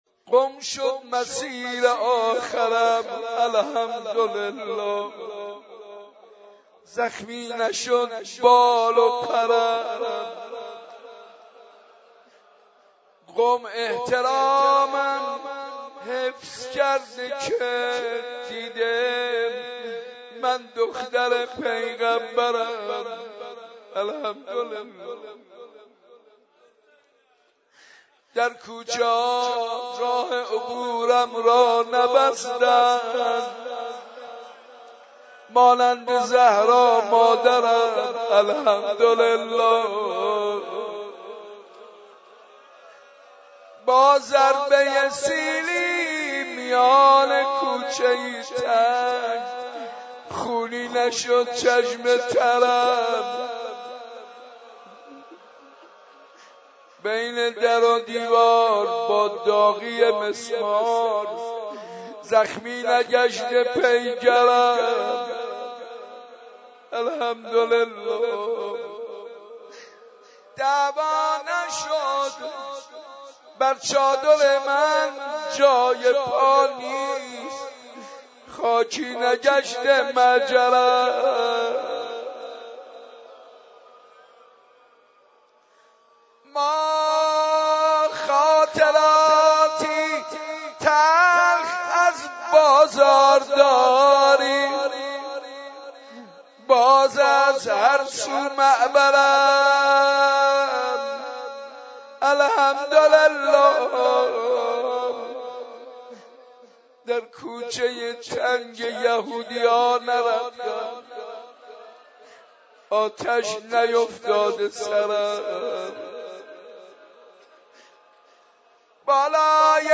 حاج منصور ارضی/مراسم هفتگی حسینیه صنف لباس فروشان